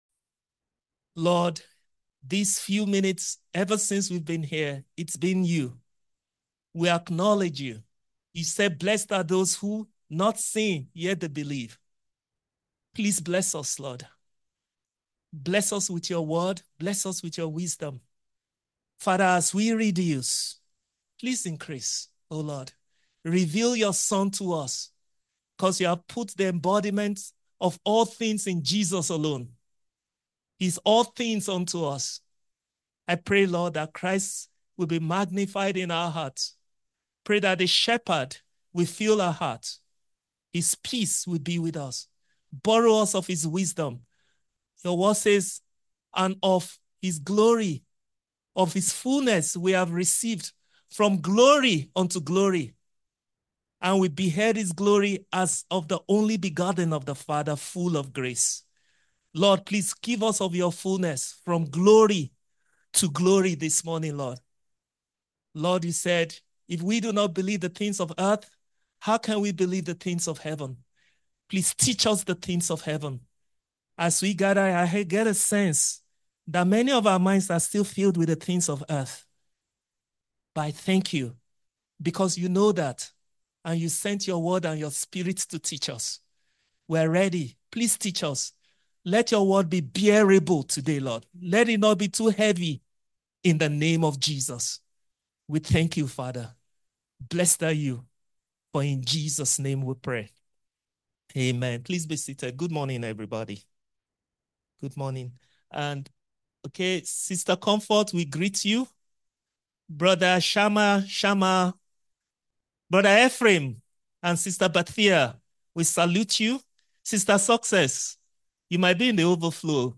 A message from the series "Sermons."